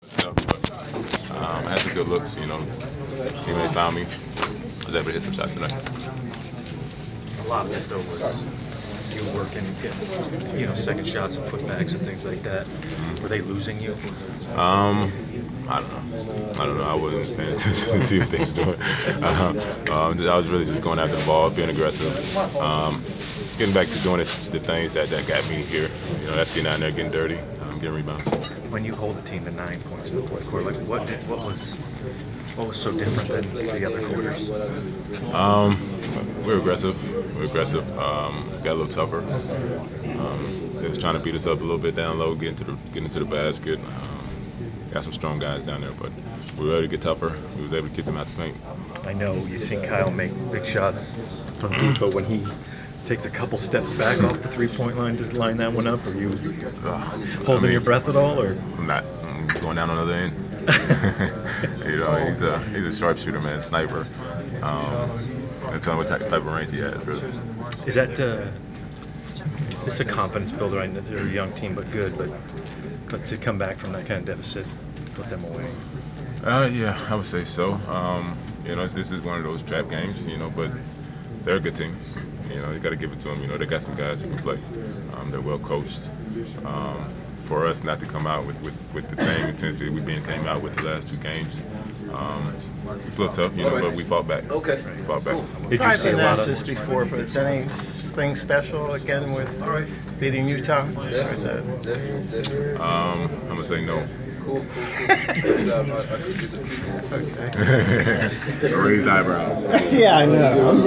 Inside the Inquirer: Interview with Atlanta Hawks Forward Paul Millsap 11/12/14
We caught up with Atlanta Hawks forward Paul Millsap after the team’s 100-97 win over the Utah Jazz on Nov. 12. He had a season-high 30 points and 17 rebounds in the victory.